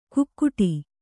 ♪ kukkuṭi